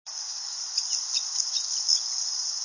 Northern Flicker
"woika" courtship call (very short example, most are longer in duration), Cheesequake State Park, North Side
flicker_northern_woika_822.wav